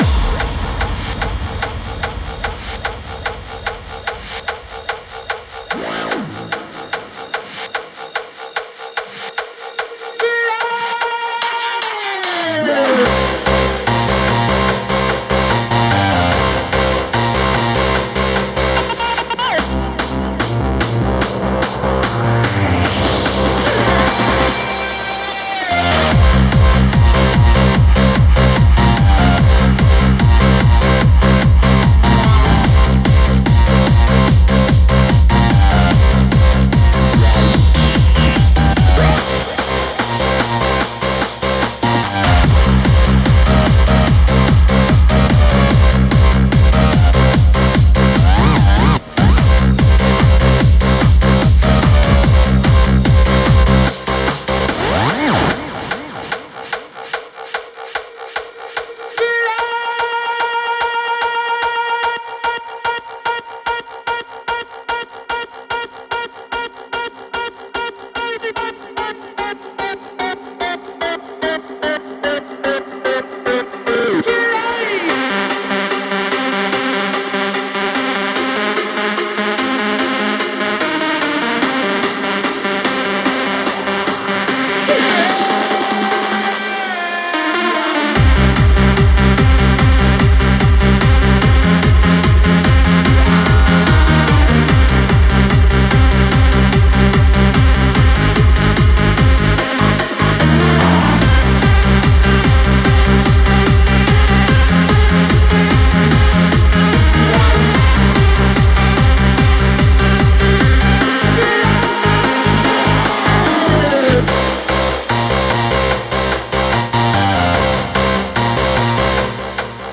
Категория: Shuffle